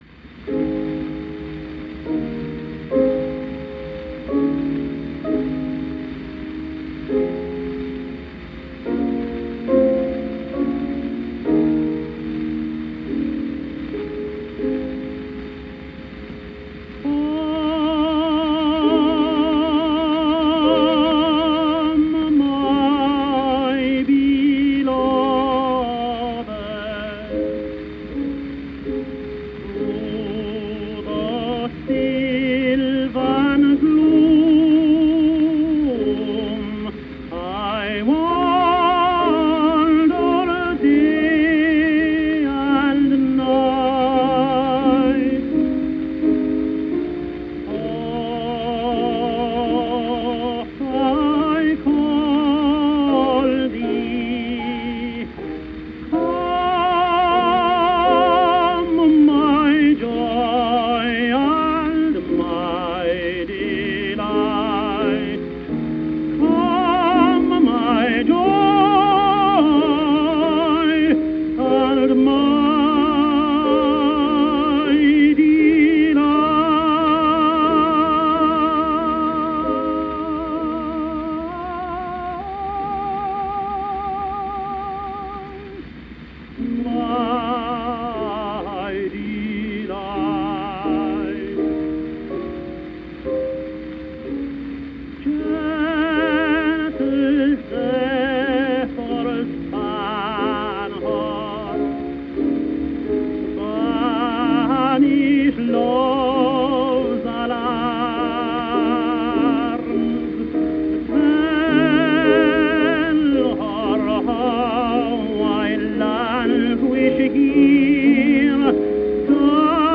John McCormack | Irish Tenor | 1884 - 1945 | History of the Tenor